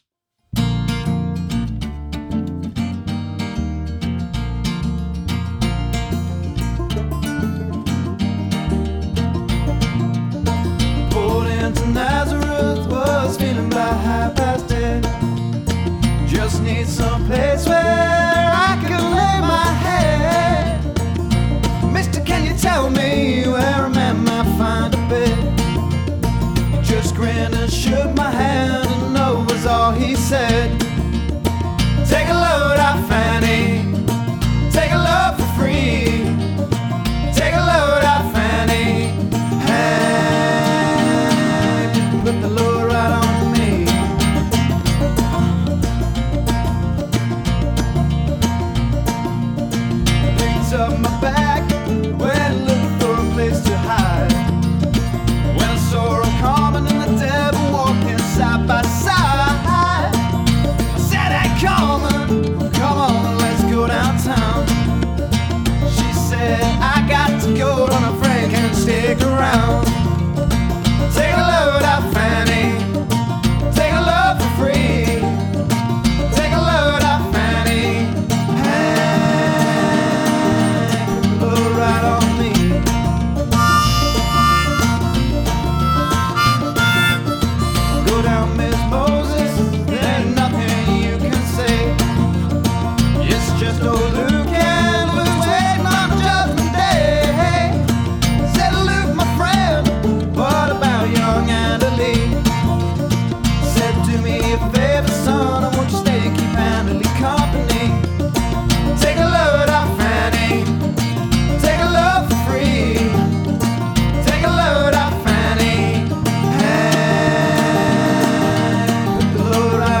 Bootleg media